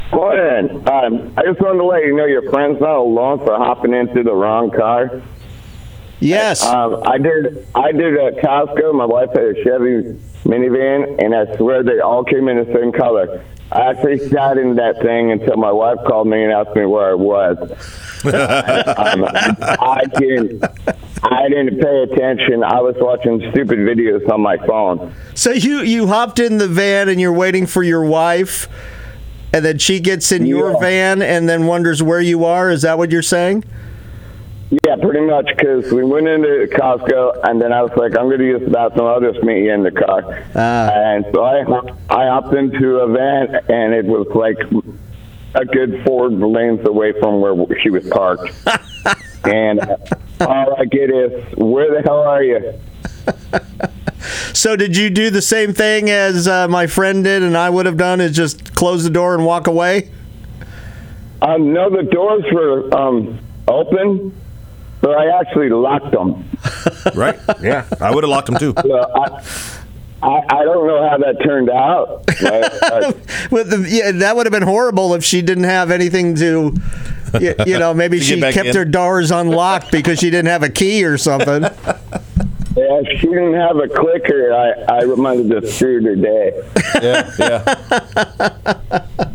MGBO-Caller-gets-into-wrong-car.mp3